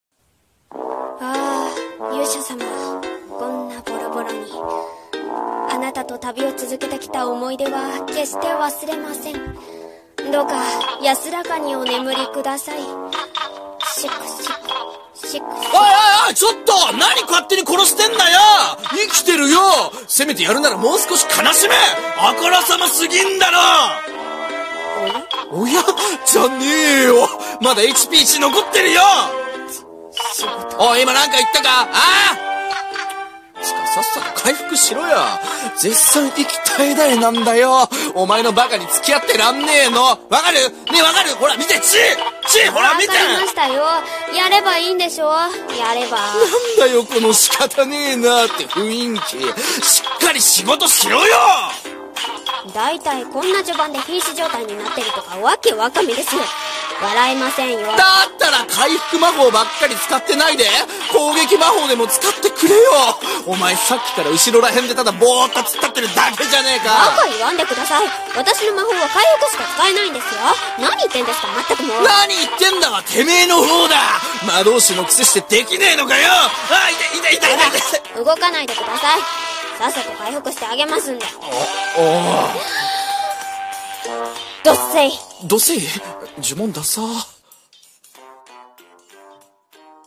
【声劇】ドッセイ